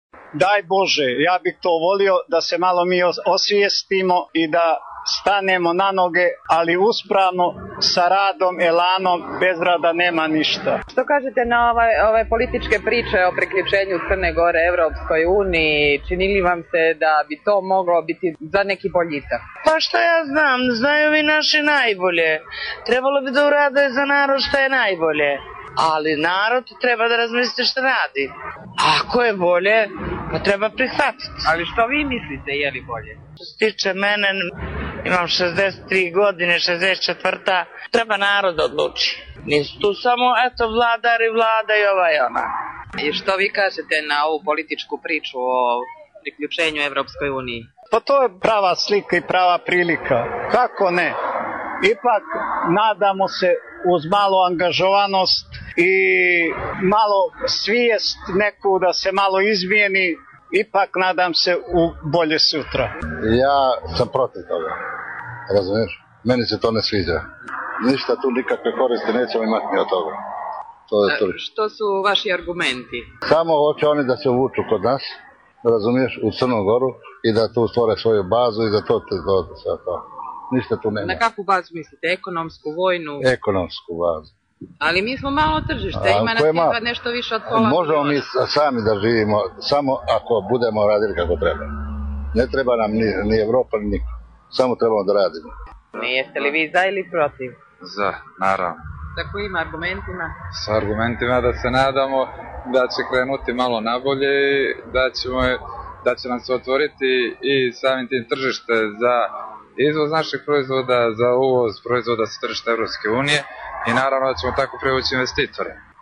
Anketa u Budvi o raspoloženju ka EU